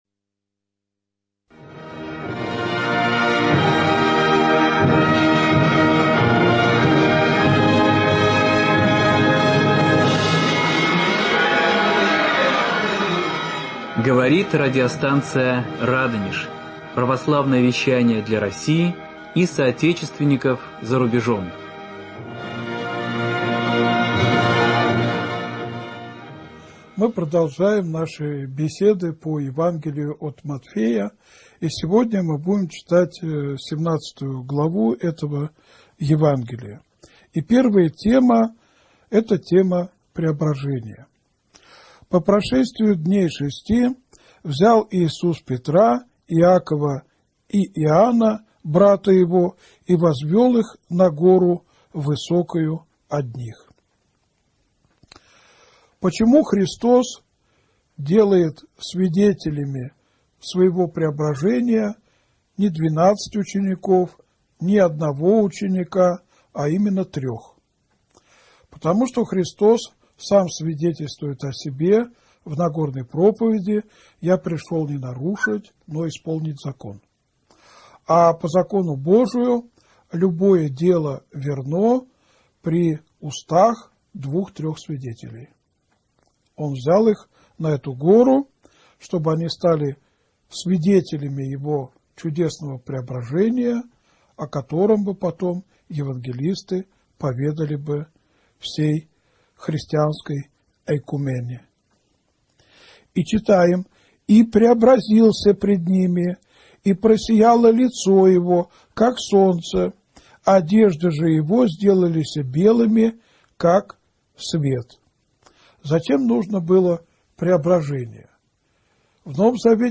Беседа 17 , 18 и 19